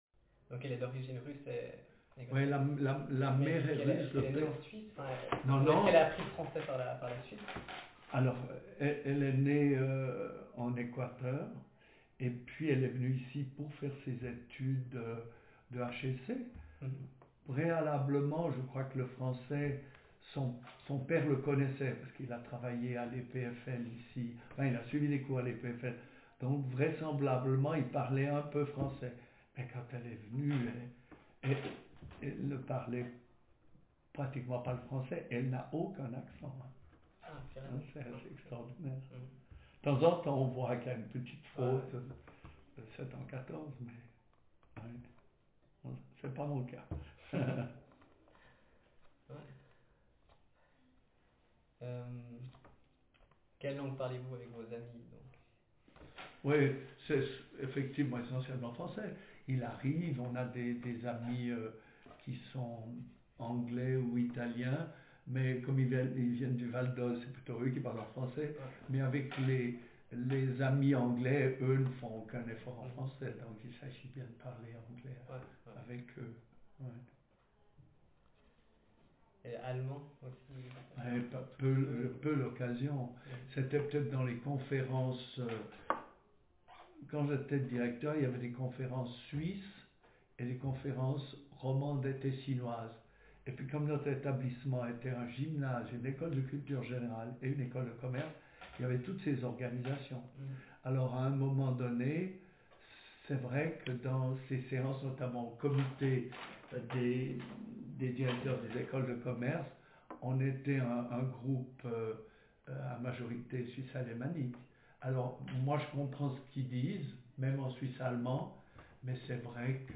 DoReCo - Language French (Swiss)
Speaker sex m Text genre personal narrative